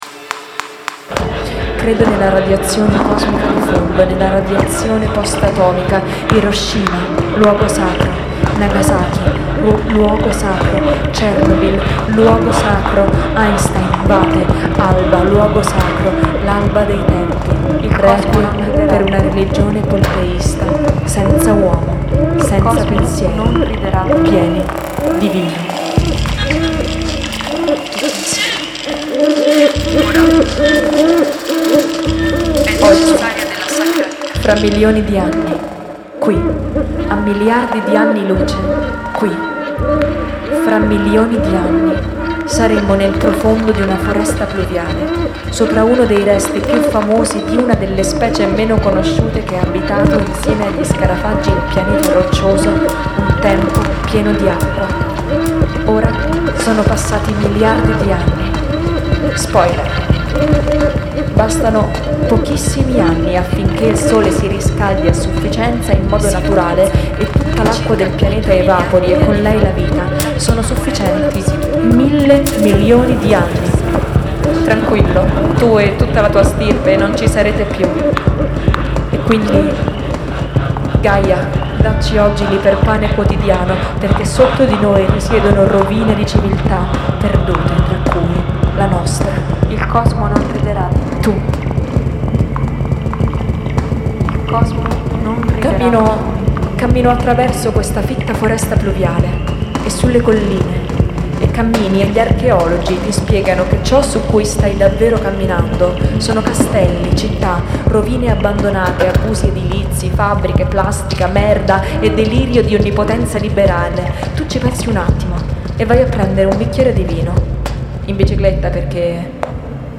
Attraverso la forma di un Techno Rito, in cui musica elettronica, parole, immagini, sono costruite attraverso una generazione artistica in tempo reale, HyperGaia racconta la storia di una divinità: una giovane raver vestita di bianco che si risveglia insieme al suo gatto radioattivo su un pianeta abbandonato 1 Miliardo di anni a partire da oggi.
Musiche composte, montate e suonate dal vivo
GAIA_LIVE_EDT_WEB.mp3